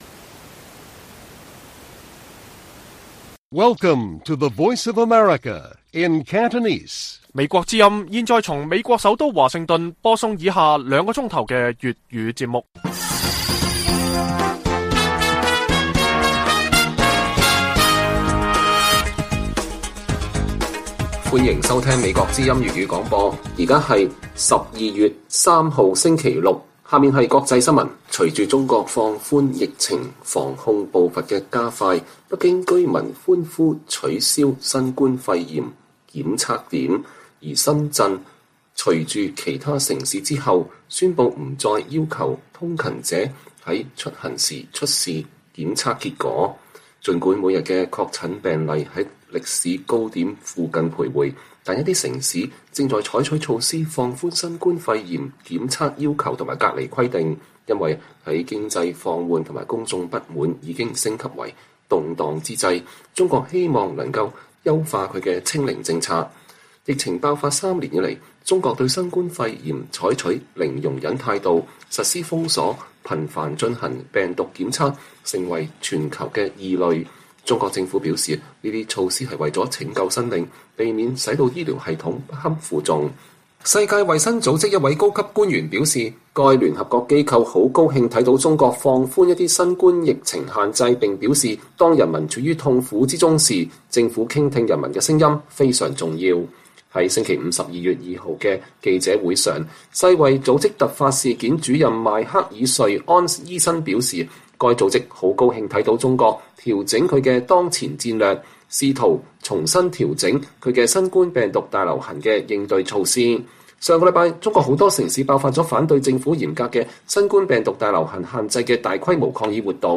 粵語新聞 晚上9-10點 : 倫敦地區議會否決中國大使館遷至前鑄幣廠